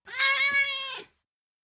Scp_cat_voice_i_1.mp3